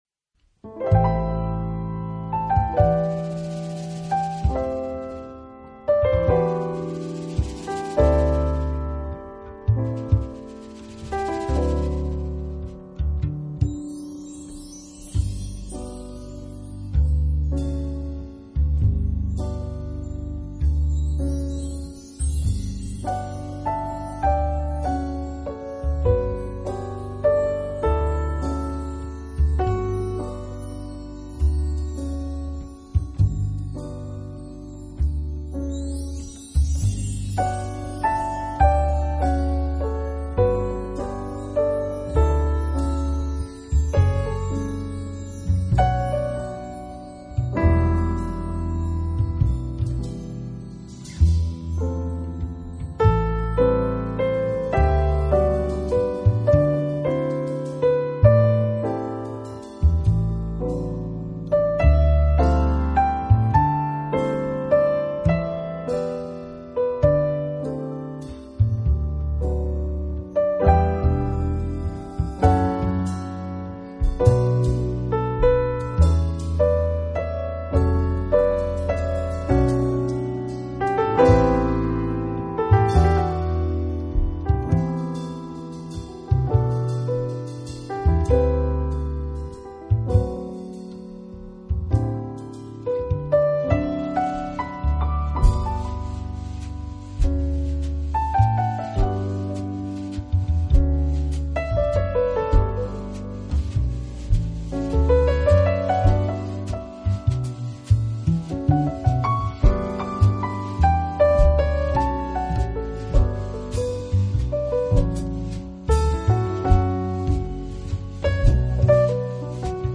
音樂類別 ：爵士樂 ． 爵士三重奏
專輯特色 ：古典，轉化為爵士的無限遐想
路西耶三重奏团来演奏，竟然蜕变成最浪漫深情的爵士乐曲，让人陶醉得喜悦忘怀。